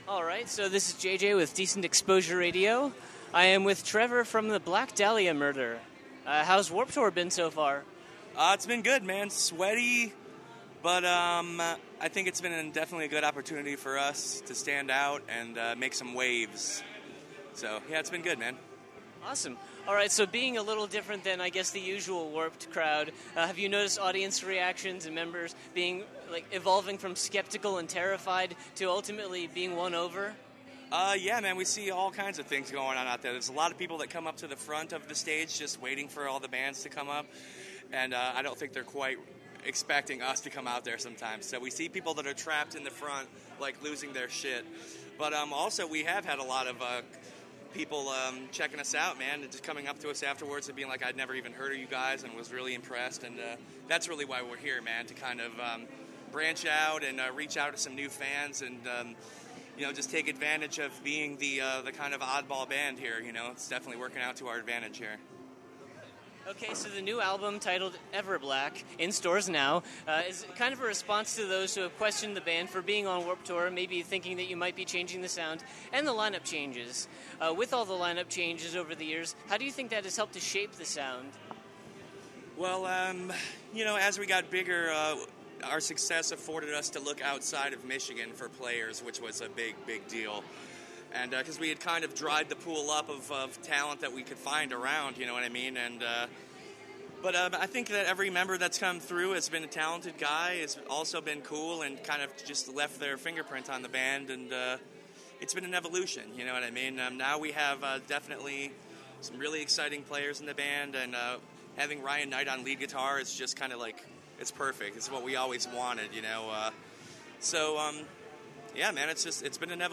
Decent Exposure Radio/Neostar Promotions got to speak with Trevor Strnad, frontman of the Black Dahlia Murder. He was a very talkative guy and won the prize for longest interview of Warped Tour (for me, anyway) at roughly 20 minutes.
28-interview-the-black-dahlia-murder.mp3